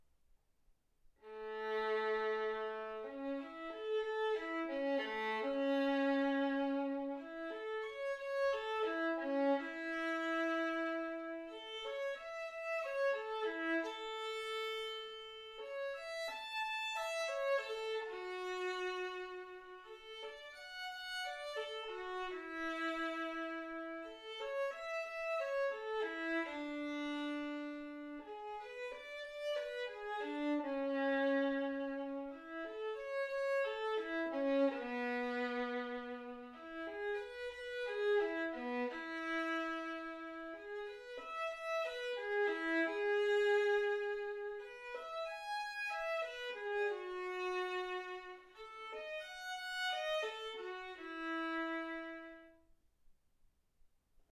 Hegedű etűdök
Classical music